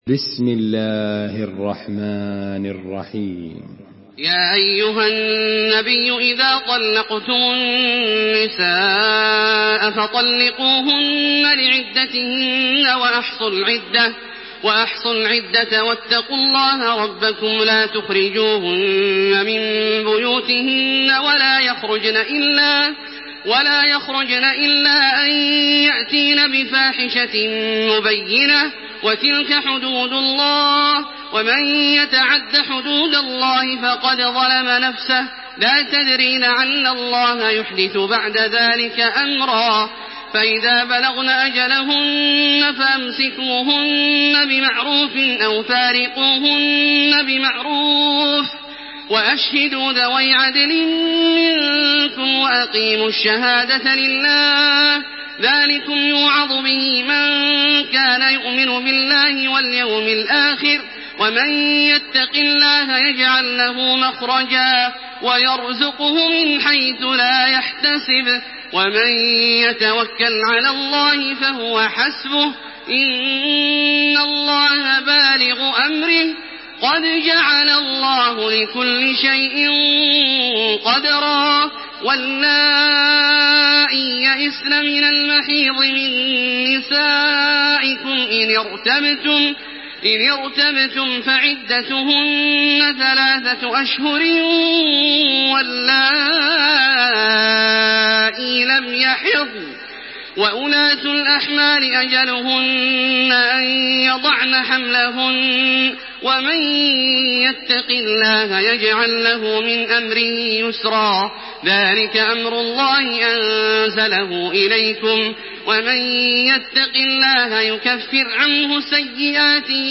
تحميل سورة الطلاق بصوت تراويح الحرم المكي 1428
مرتل